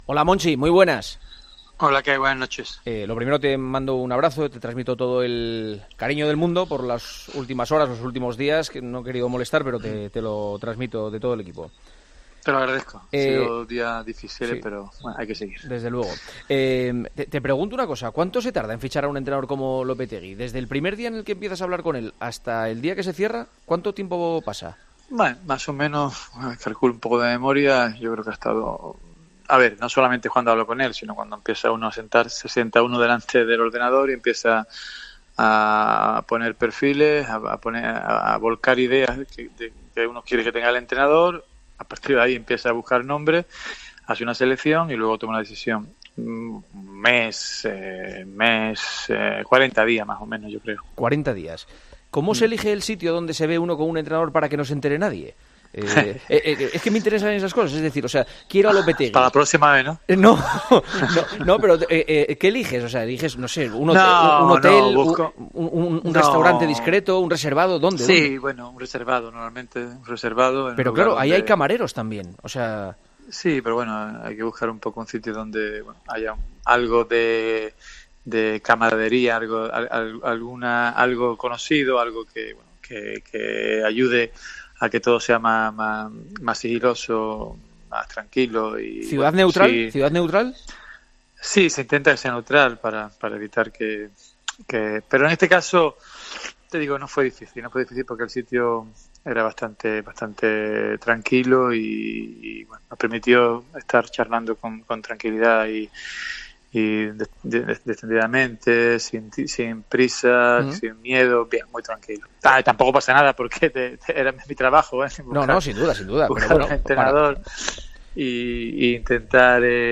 Hablamos en El Partidazo de COPE con el director deportivo del Sevilla el día de la presentación de Julen Lopetegui.